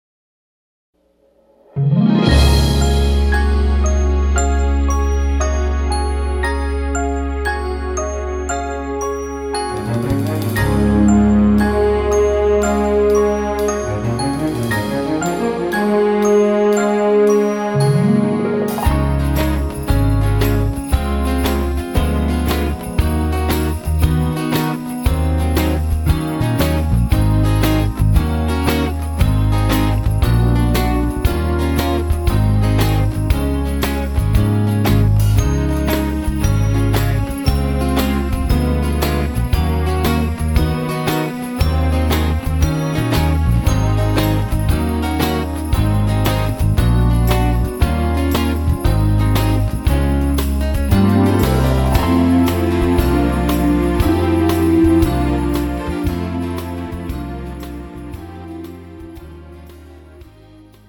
고음질 반주 다운로드.